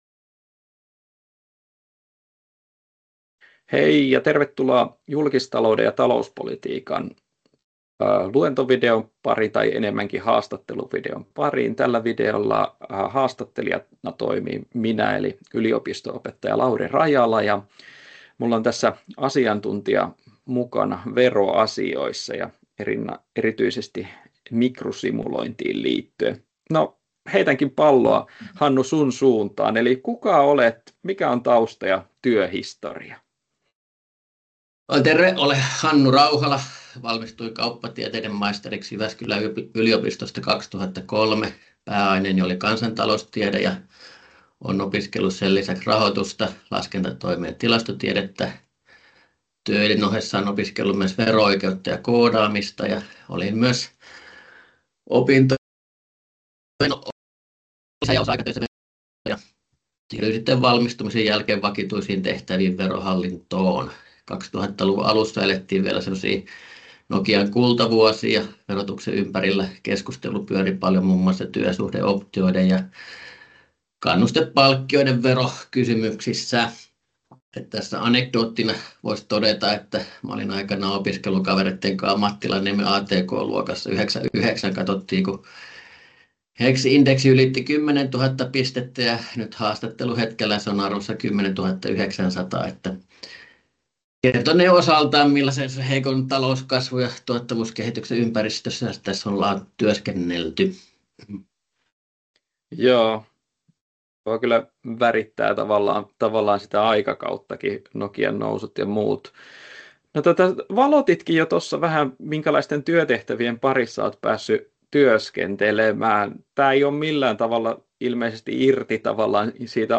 Haastattelu verolaskelmista — Moniviestin